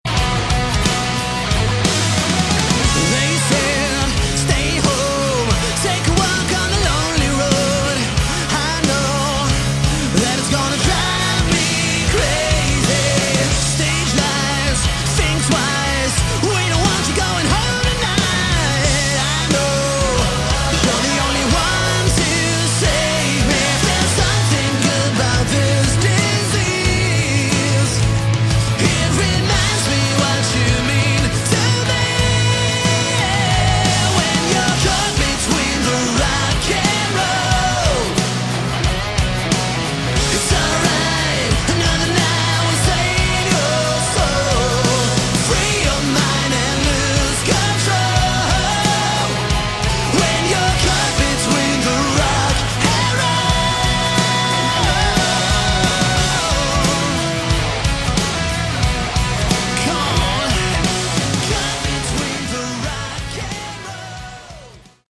Category: Hard Rock
Vocals
Bass
Drums
Guitar